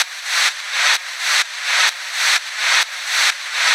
VEH1 Fx Loops 128 BPM
VEH1 FX Loop - 37.wav